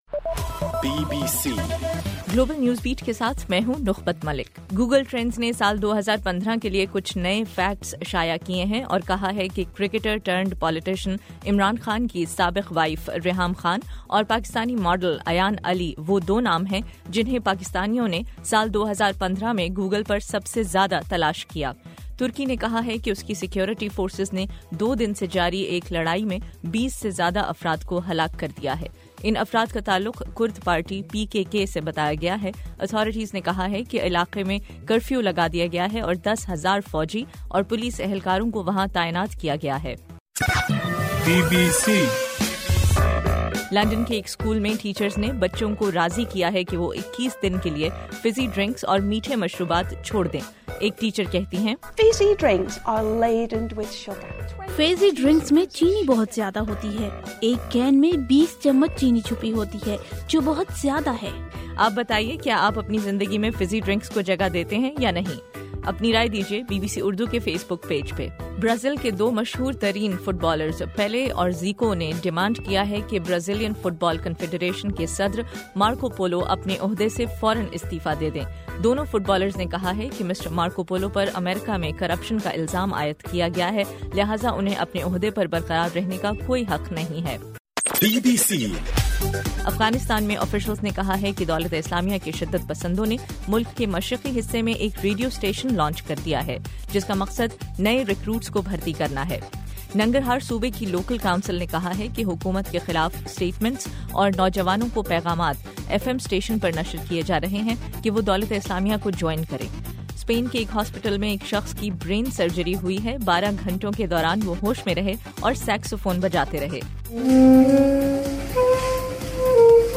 دسمبر 17 رات 8 بجے کے گلوبل نیوز بیٹ بلیٹن